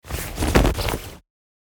Free Foley Sound Effects Download.
Jacket-movement-putting-on.mp3